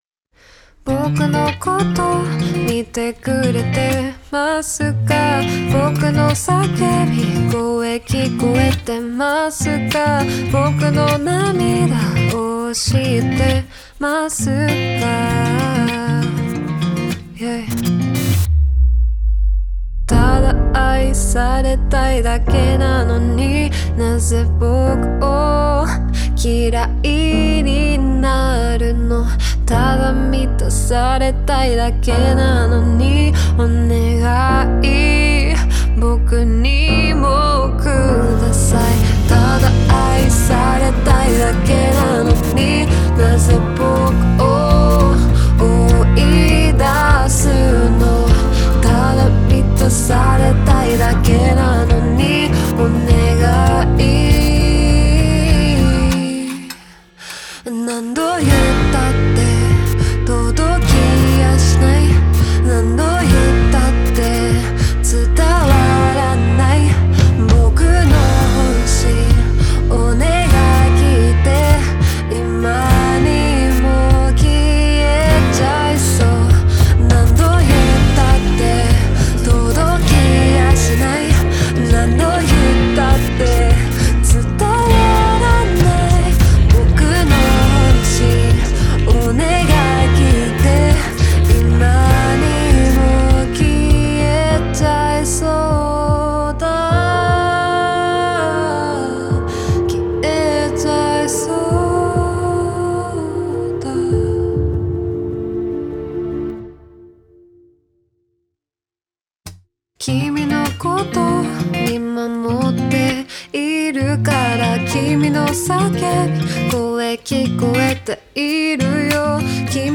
オリジナルKey：「Bb